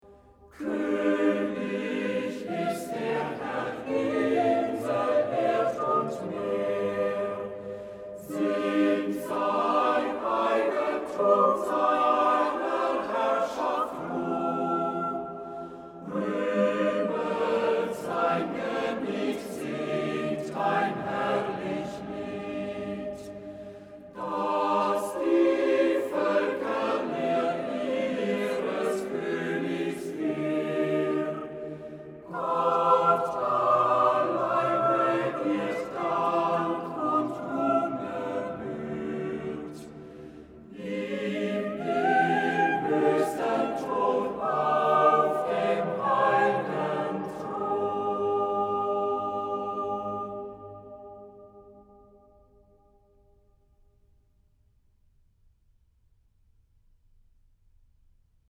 Chormusik zum Genfer Psalter, Berliner Domkantorei